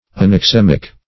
([a^]n`[o^]ks*[=e]"m[i^]k), a.